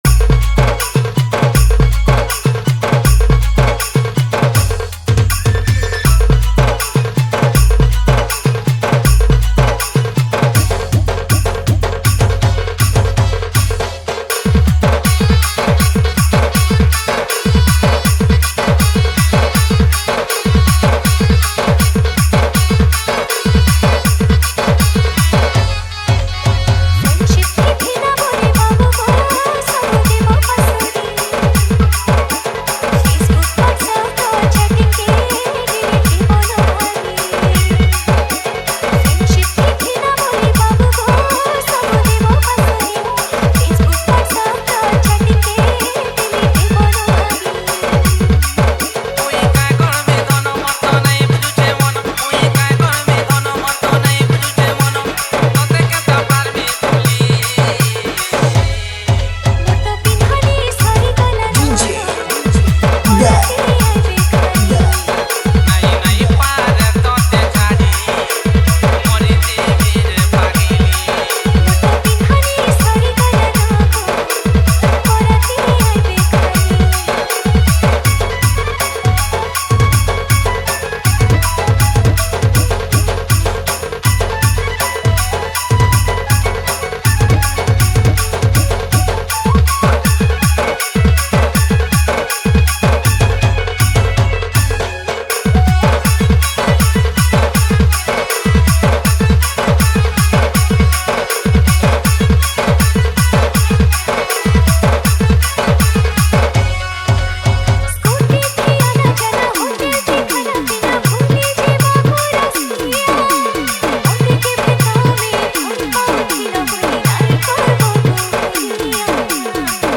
Category:  Sambalpuri Dj Song 2025